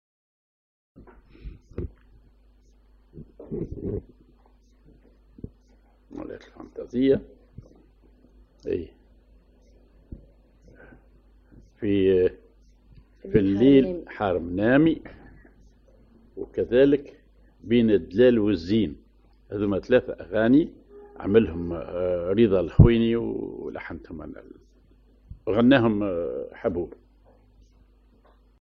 ar محير سيكاه
أغنية